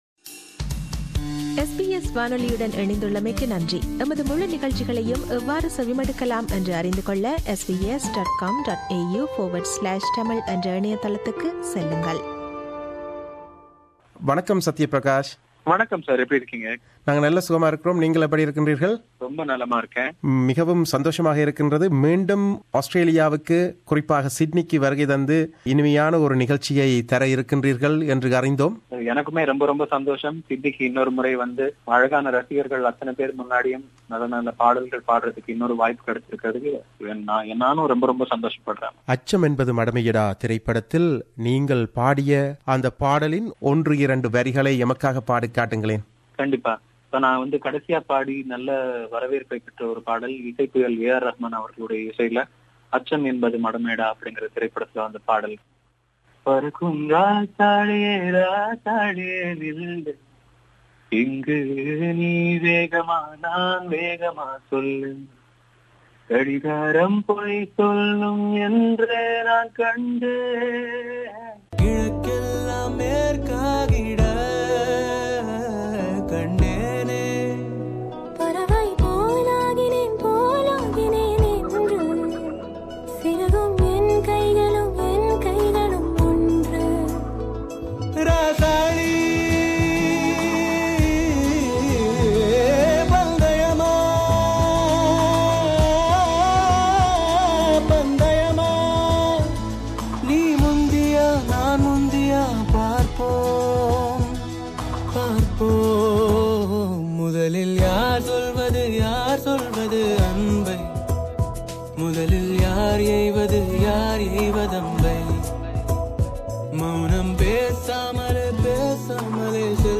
Interview with Playback Singer Sathyaprakash